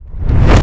casting_charge_whoosh_buildup4.wav